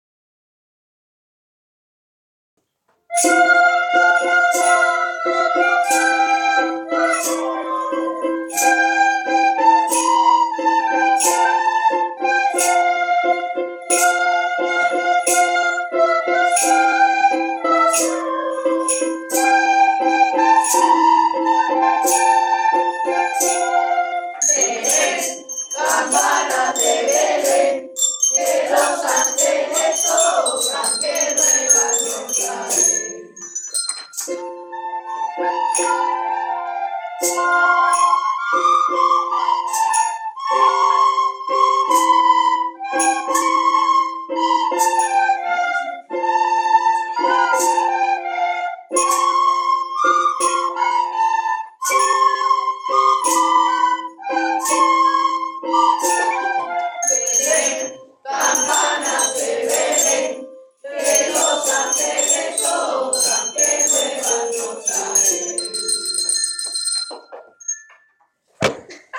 CONCURSO DE VILLANCICOS
El alumnado de 1º ESO y 2º ESO han montado villancicos y hemos realizado un concurso.
Villancico-1°B-Campana-Sobre-Campana.mp3